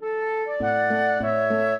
flute-harp
minuet14-12.wav